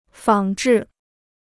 仿制 (fǎng zhì): to copy; to imitate.